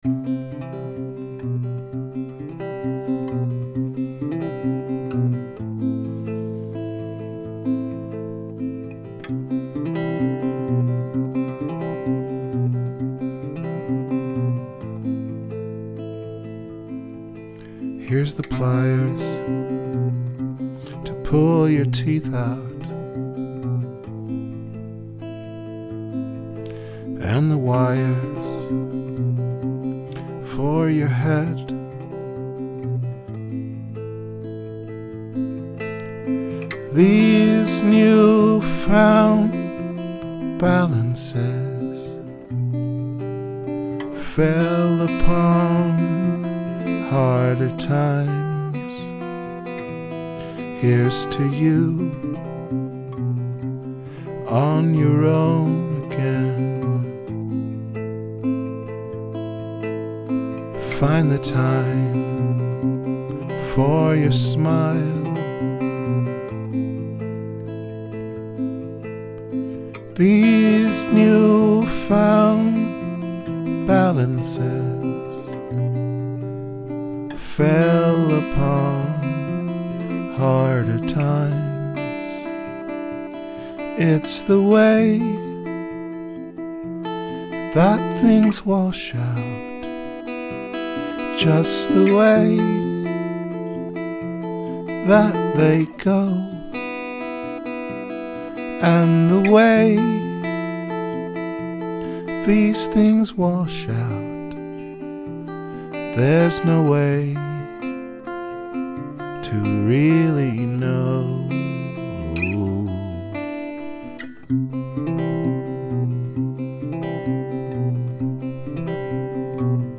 just the room.
Category: Rehearsal recordings